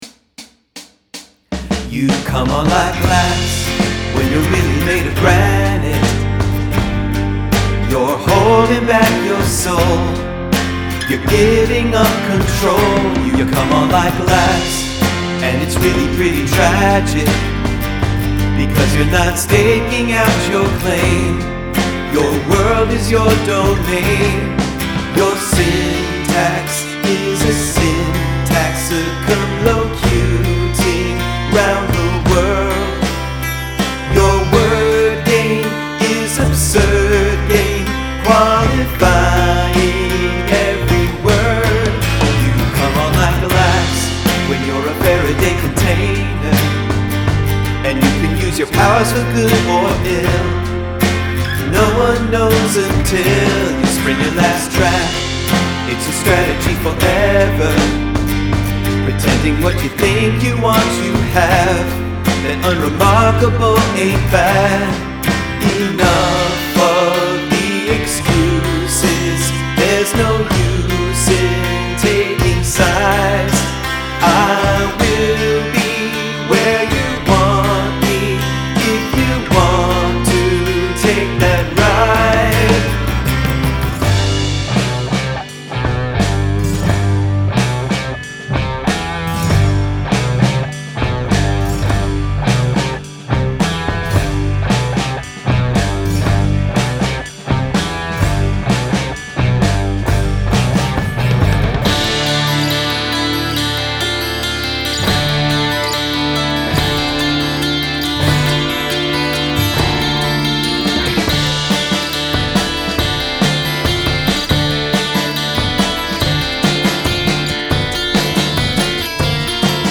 Verses at 80bpm
Accelerate from 80bpm to 140 bpm
Bridge at 140 bpm
Digging the solo.